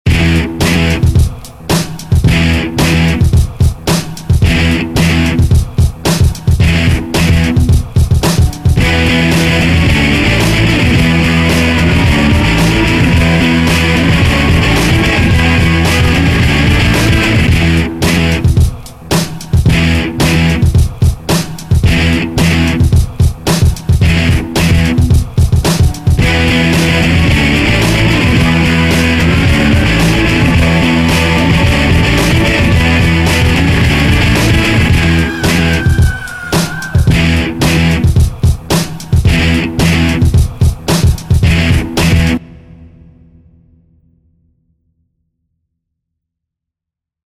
College Rock, Frat Party Gone Wrong, Horror, Headbanger
Gothic Rock Electric Guitar, Bass, Drums, Screams F/TV